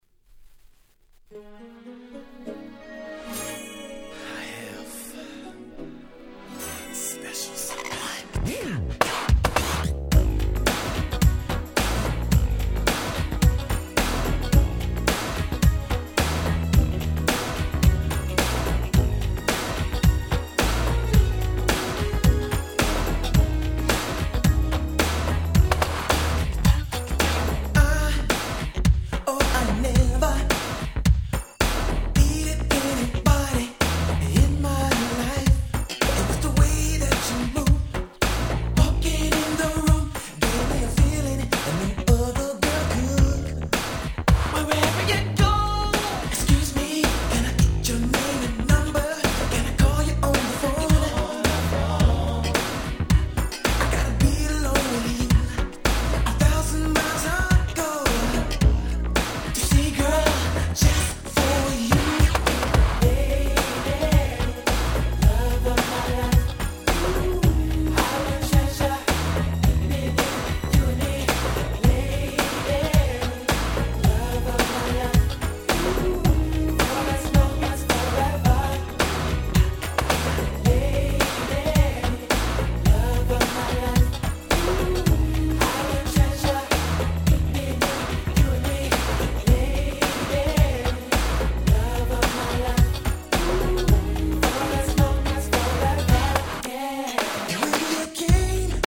92' 人気New Jack Swing！！